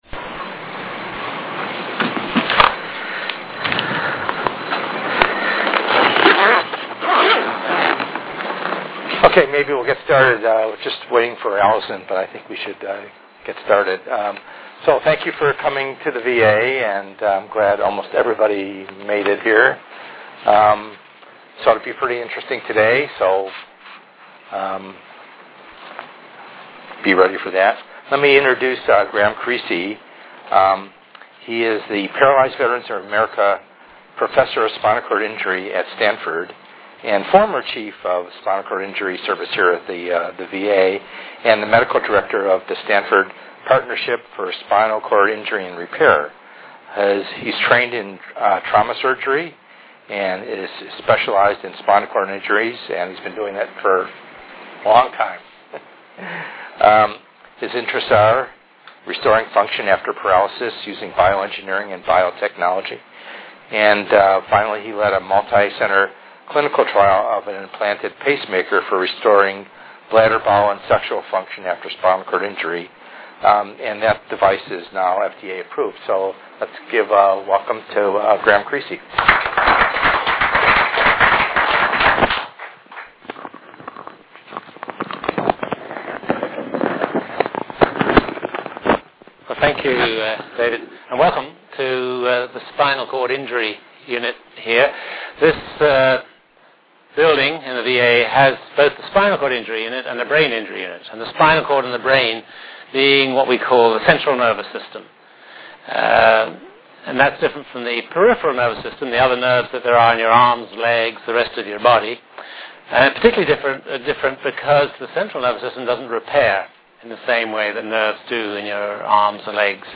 FRCSEd VA Palo Alto Health Care System This tour will be held at the VA Palo Alto Health Care System campus in the Spinal Cord Injury Service.